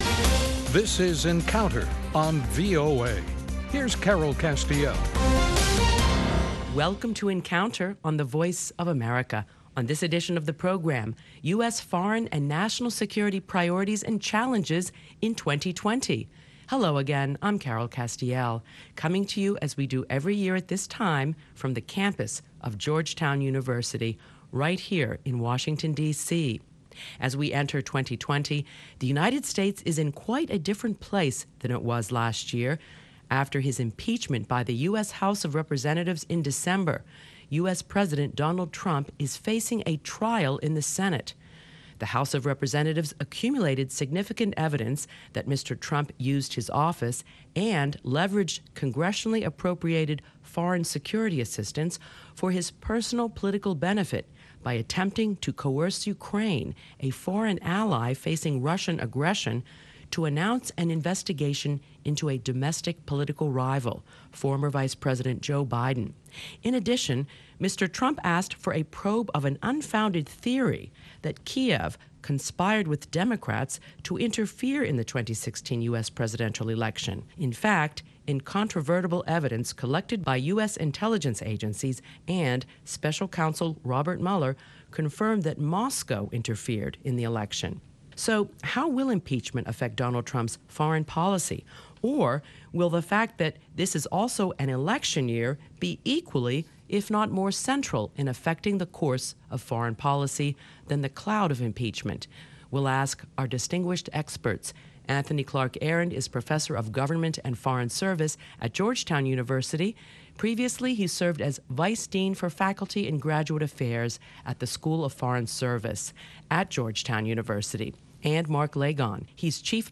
This program was recorded BEFORE the US strike that killed Iranian General Qassim Suleimani. What are the primary foreign policy and national security challenges facing the United States as President Donald J. Trump enters his fourth year in office under the cloud of impeachment?